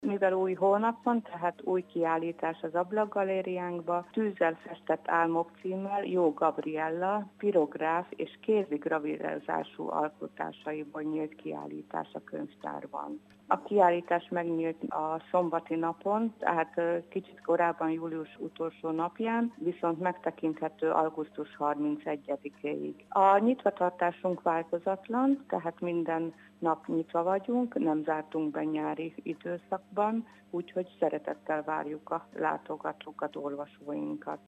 A járvány miatt több területen újragondolták Taksony költségvetését, azonban az elnyert pályázatokat meg tudják valósítani. Legutóbb gyalogjárda építésére nyertek 20 millió forintnyi pályázati pénzt, illetve túl vannak már egy útfelújításon. Kreisz László polgármestert hallják.